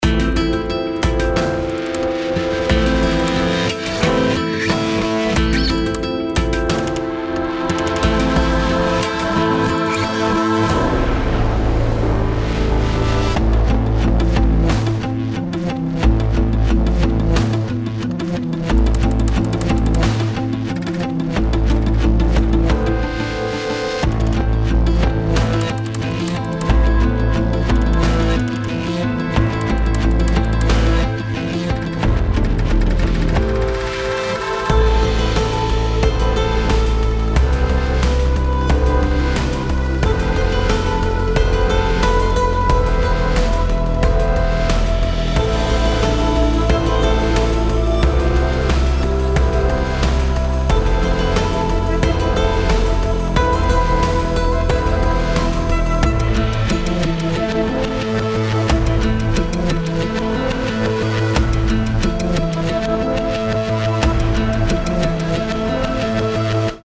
INSPIRING CINEMATIC GUITARSCAPES
ACOUSTIC GUITARS FOR THE PRO COMPOSER